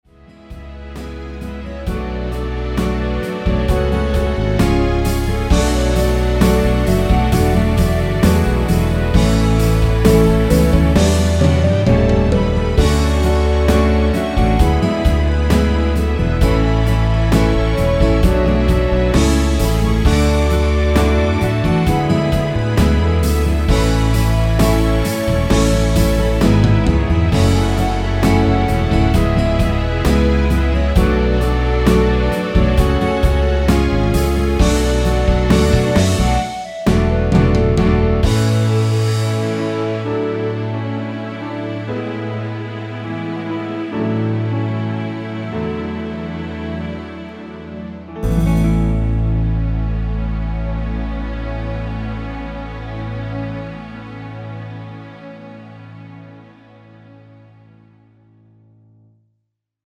원키에서(-1)내린 멜로디 포함된 (짧은편곡) MR입니다.
앞부분30초, 뒷부분30초씩 편집해서 올려 드리고 있습니다.
중간에 음이 끈어지고 다시 나오는 이유는